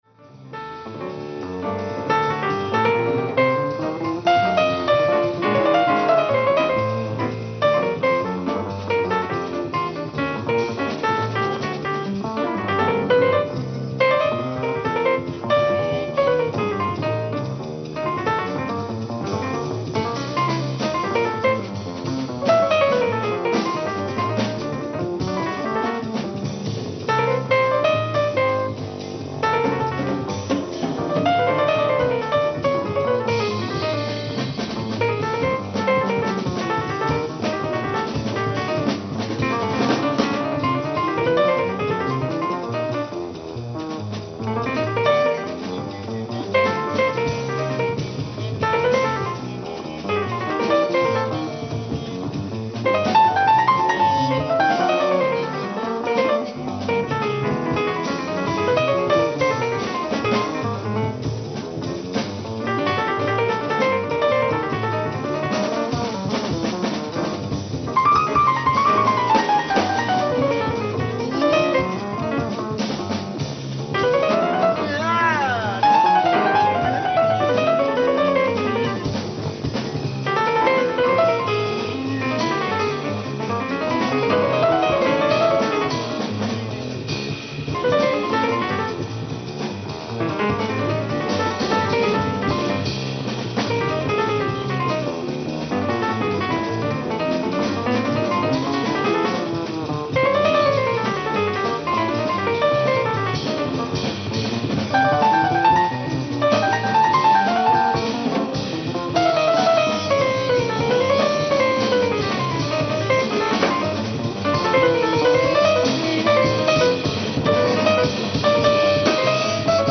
ライブ・アット・アンティーブ・ジャズ、ジュアン・レ・パン、フランス 07/26/1986
※試聴用に実際より音質を落としています。